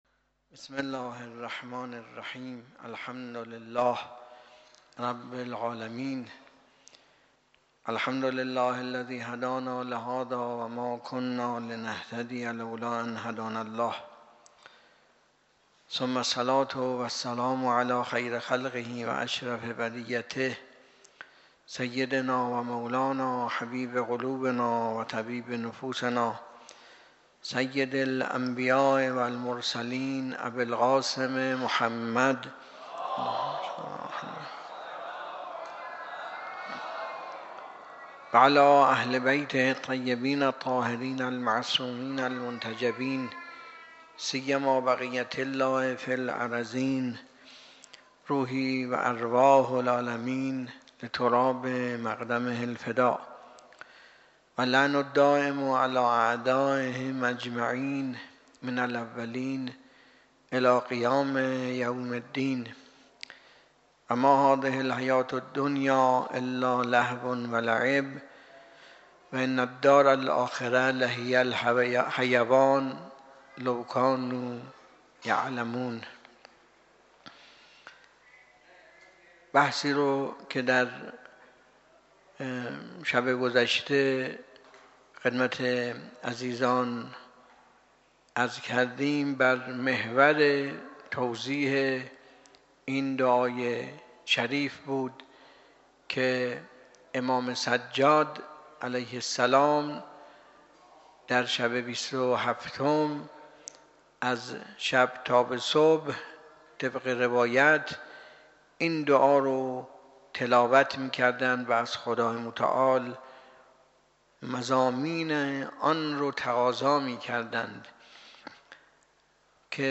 درس اخلاق| دفتر مقام معظم رهبری در قم- ۱۹ فروردین ۱۴۰۳
درس اخلاق